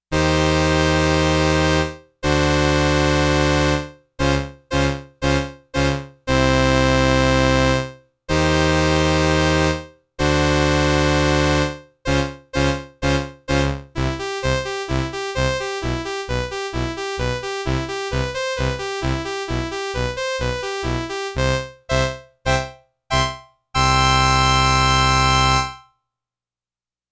The Roland RS-202 is a classic 1970s "string ensemble" keyboard, with a sound and and an ensemble effect similar to the classic Solina.
02 STRINGS I
02 STRINGS I.mp3